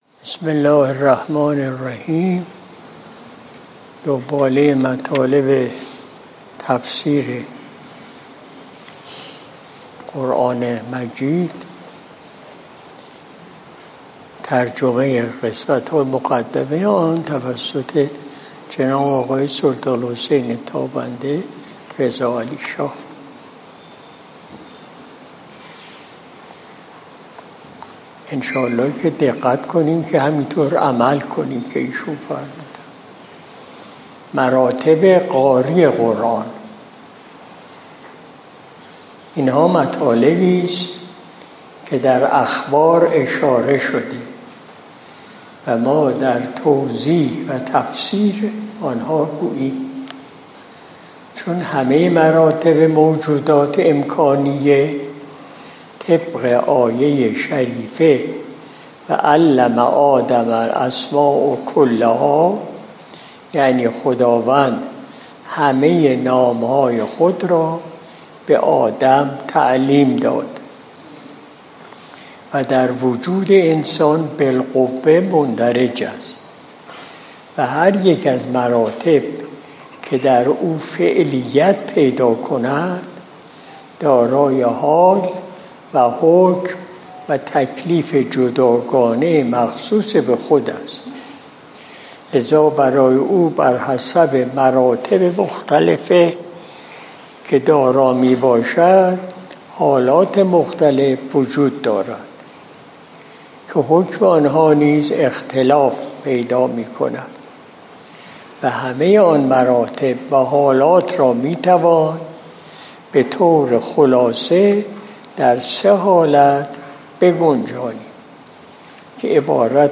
مجلس صبح جمعه ۲۰ مرداد ماه ۱۴۰۲ شمسی